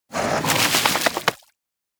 Sfx_creature_snowstalkerbaby_dry_fur_01.ogg